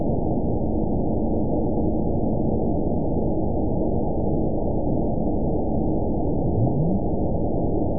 event 922717 date 03/17/25 time 21:42:44 GMT (8 months, 2 weeks ago) score 9.20 location TSS-AB02 detected by nrw target species NRW annotations +NRW Spectrogram: Frequency (kHz) vs. Time (s) audio not available .wav